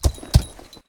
horses / walk1.ogg